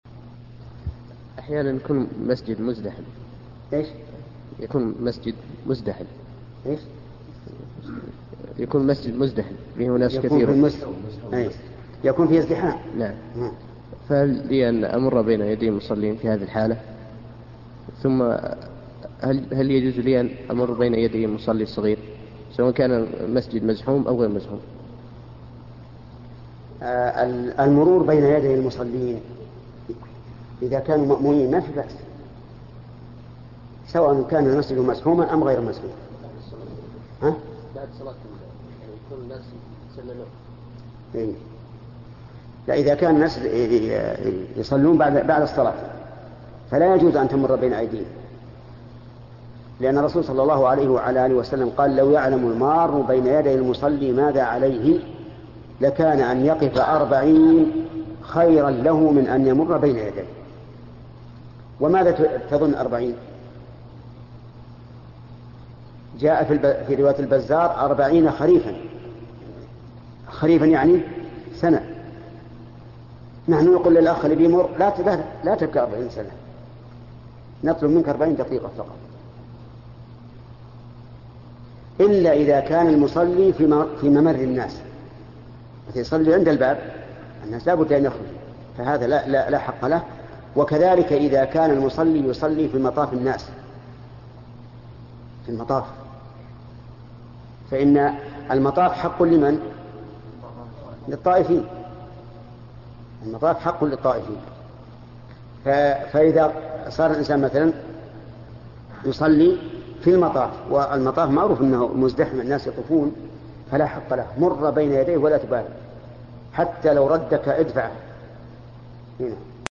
سئل الشيخ ابن عثيمين رحمه الله تعالى السؤال التالي :